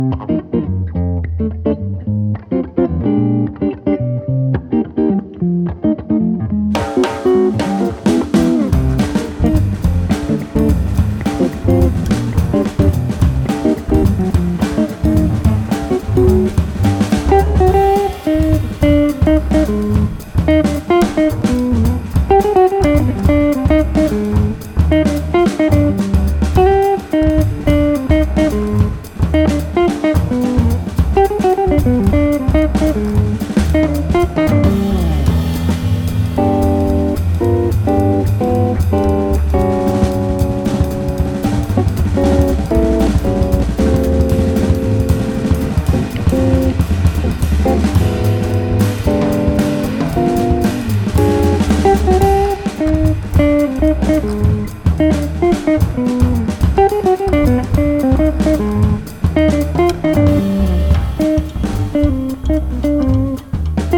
爵士音樂、發燒音樂